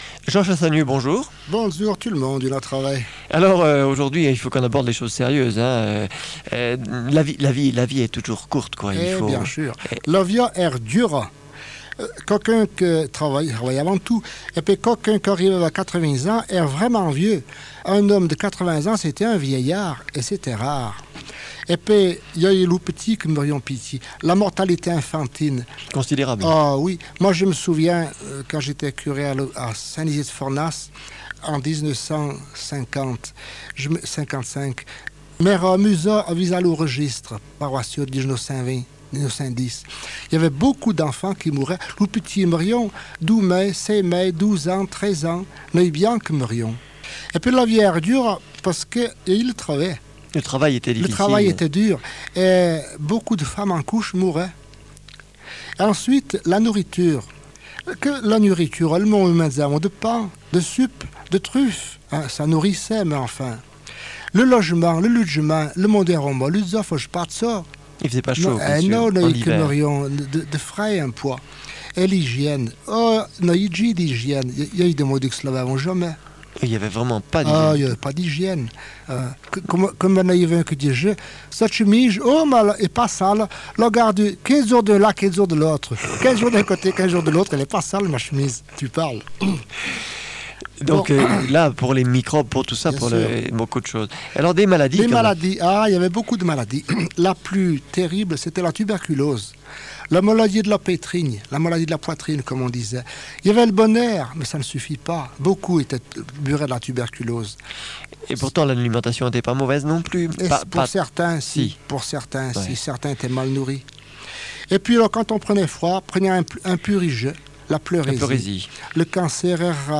Entretiens en patois du haut Forez
émission diffusée chaque jeudi sur RCF Saint-Etienne